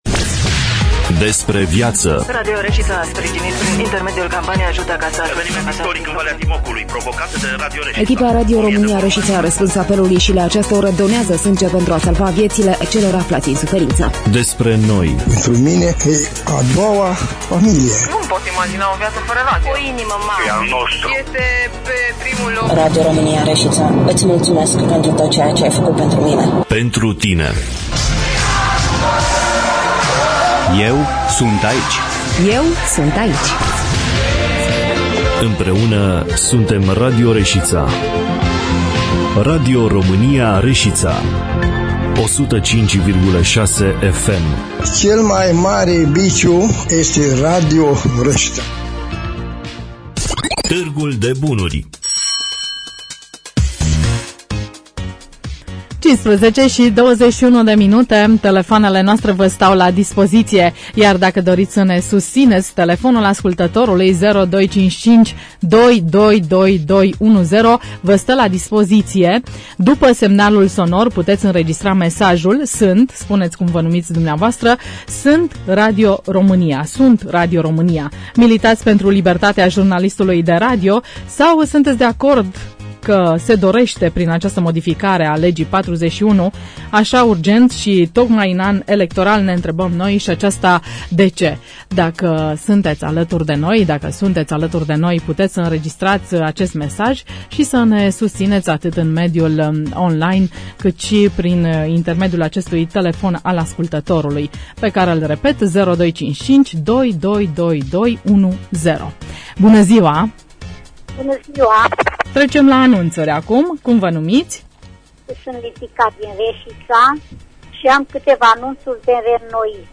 Înregistrarea emisiunii „Târgul de bunuri” de luni, 29.02.2016, difuzată la Radio România Reşiţa.